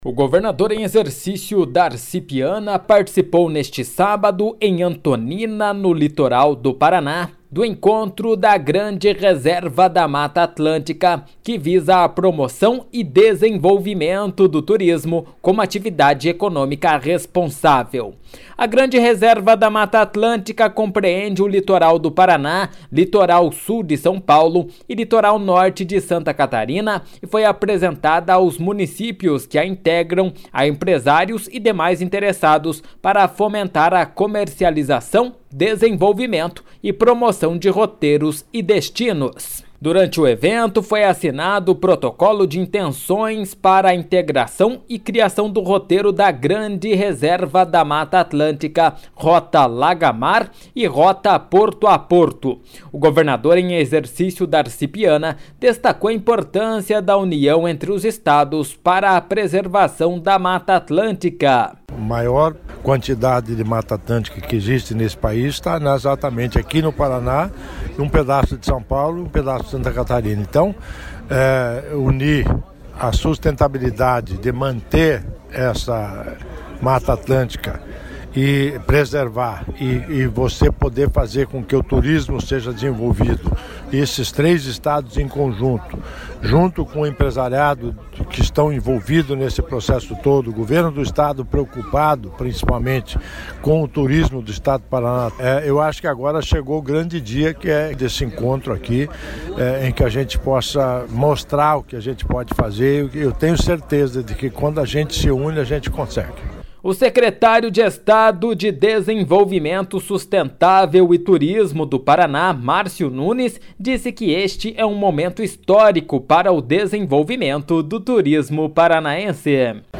Durante o evento foi assinado o protocolo de intenções para integração e criação do roteiro da Grande Reservada Mata Atlântica, Rota Lagamar e Rota Porto a Porto.O governador em exercício, Darci Piana, destacou a importância da união entre os estados para preservação da Mata Atlântica.// SONORA DARCI PIANA.// O secretário de Estado de Desenvolvimento Sustentável e Turismo do Paraná, Marcio Nunes, disse que este é um momento histórico para o desenvolvimento do turismo paranaense.// SONORA MARCIO NUNES.// O presidente da Paraná Turismo, Jacob Mehl, disse que nunca viu uma reunião tão representativa para o turismo paranaense.// SONORA JACOB MEHL.// Entre os estados de São Paulo, Paraná e Santa Catarina, está o último grande remanescente contínuo da mata atlântica, chamado de Grande Reserva Mata Atlântica.